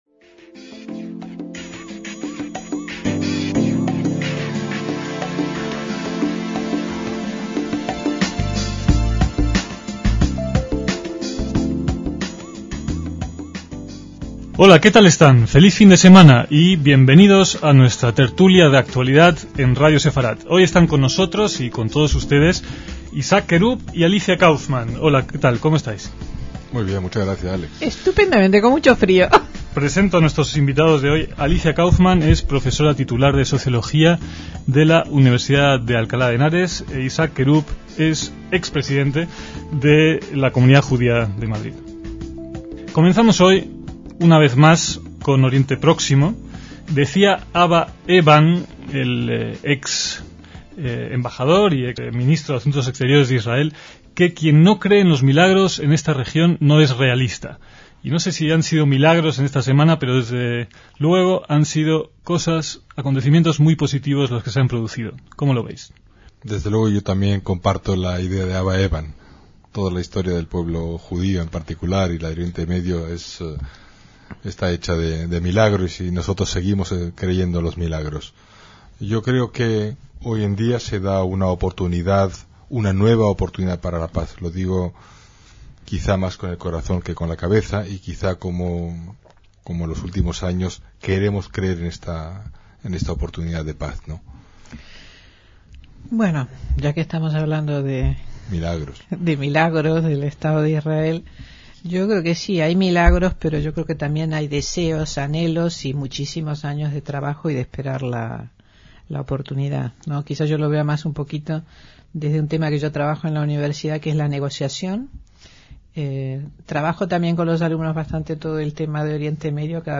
DECÍAMOS AYER (5/3/2005) - Cuando se emitió originalmente este debate hace 20 años, asomaba un rayo de esperanza de recuperación del proceso de paz entre Israel y los palestinos.